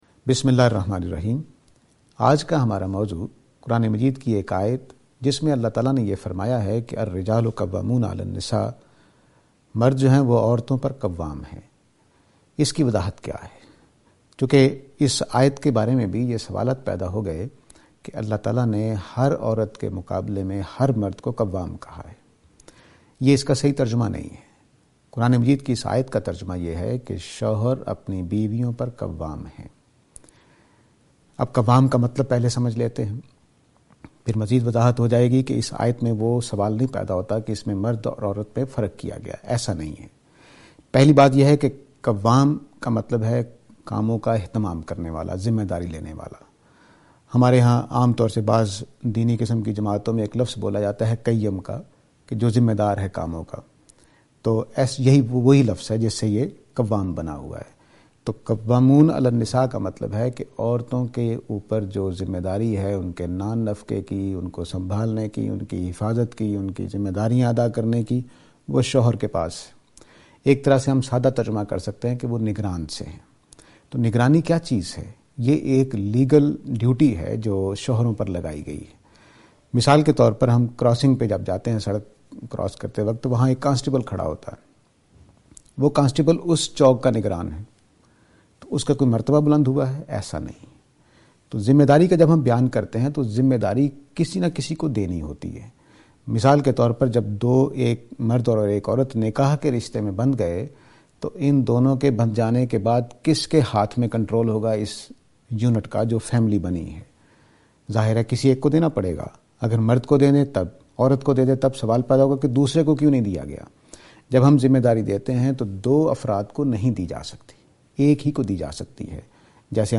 This lecture is and attempt to answer the question "Do men dominate women?".